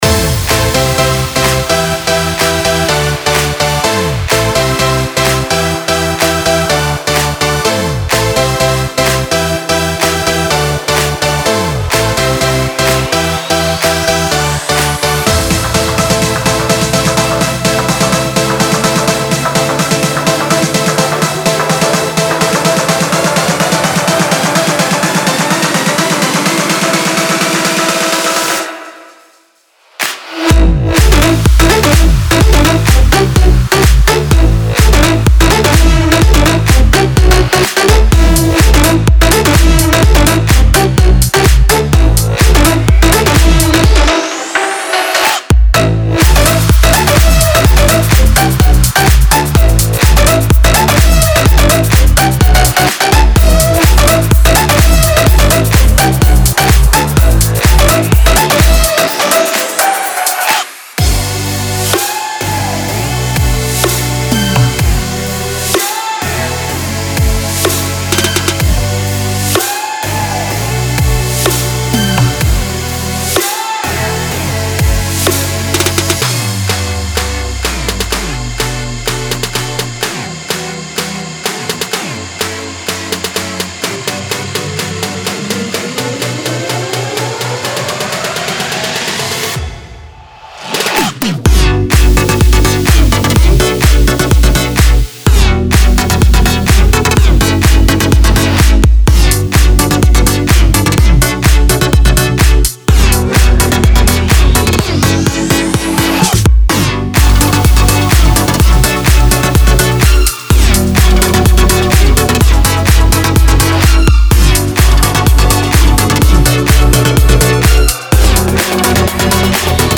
此包装中不仅包括生鲜的零食，还有令人赞叹的和弦，旋律和节拍的分解音，这将为您的制作带来新的色彩。